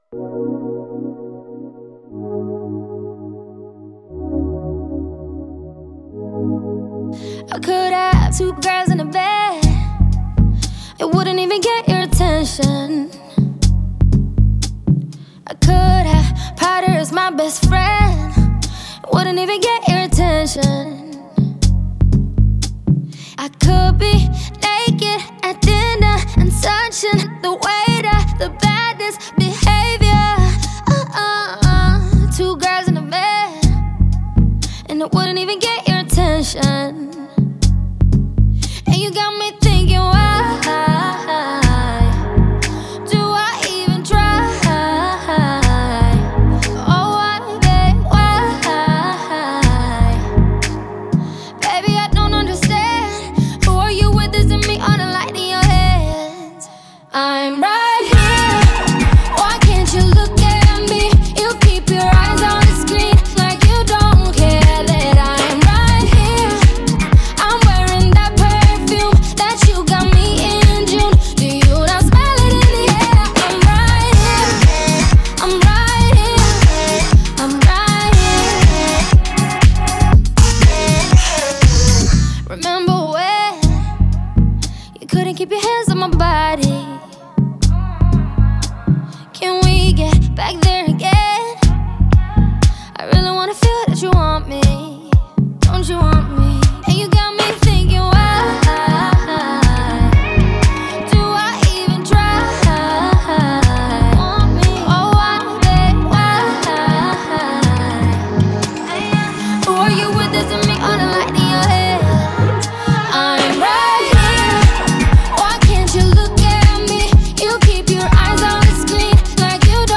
энергичная поп-песня